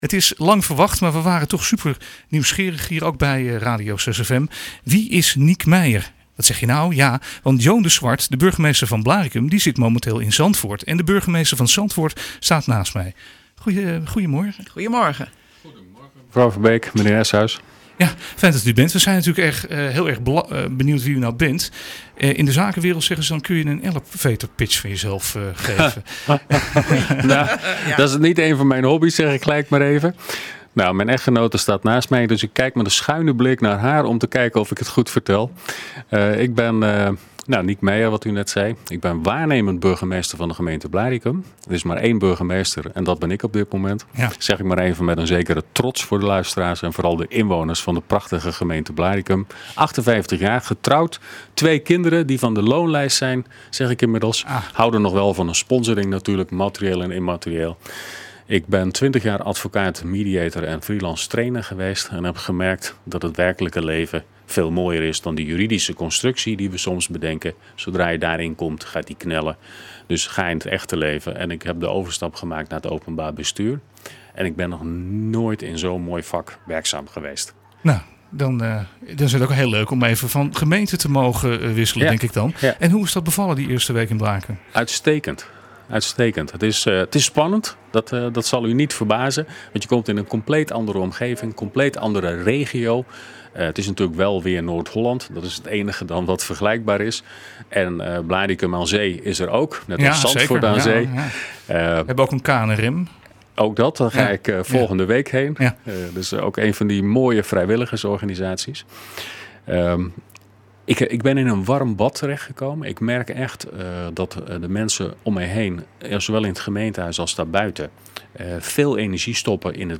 Van 11 tot 27 september 2017 ruilen Joan de Zwart-Bloch, burgemeester van Blaricum en Niek Meijer, burgemeester van Zandvoort tijdelijk van baan. We willen graag kennis maken met deze tijdelijke burgemeester en Niek Meijer was in de 6FM studio om te vertellen over zijn eerste week in Blaricum.